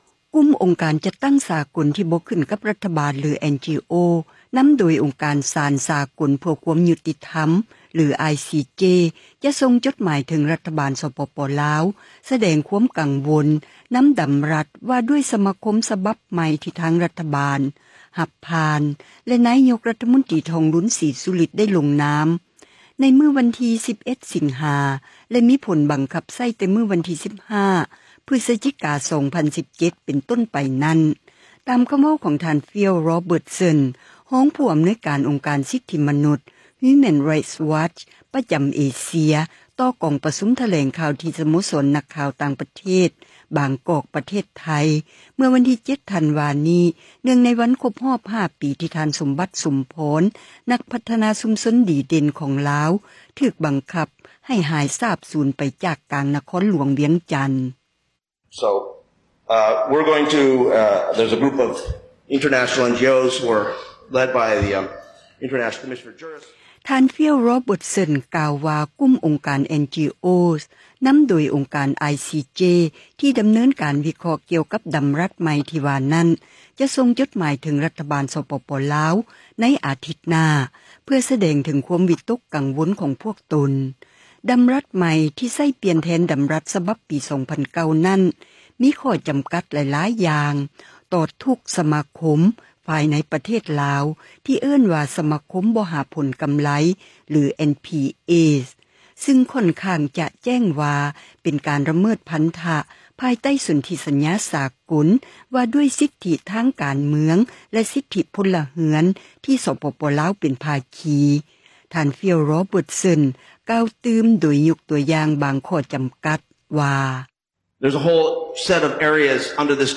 ກ່າວຕໍ່ກອງປະຊຸມ ຖແລງຂ່າວ ທີ່ ສະໂມສອນ ນັກຂ່າວຕ່າງປະເທດ ບາງກອກ ປະເທດໄທ